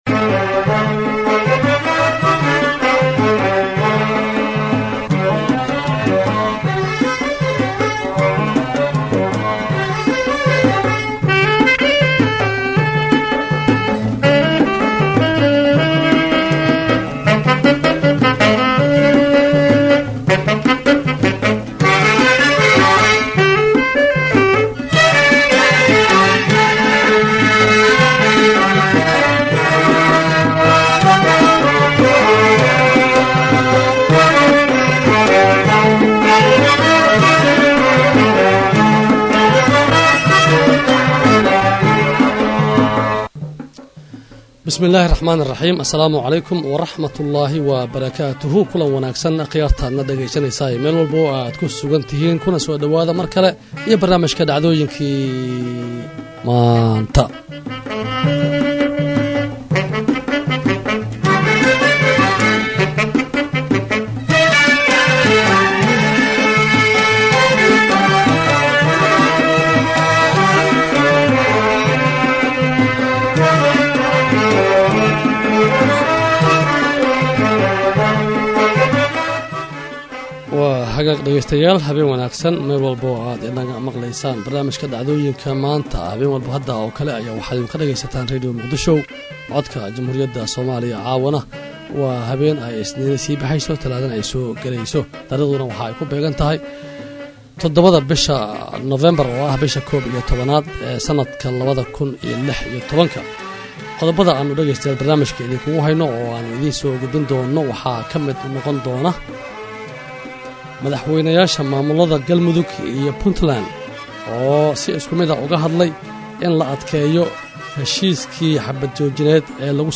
Barnaamijka waxaa diirada lagu saaraa raad raaca ama falanqeynta dhacdooyinka maalintaas taagan, kuwa ugu muhiimsan, waxaana uu xambaarsan yahay macluumaad u badan Wareysiyo.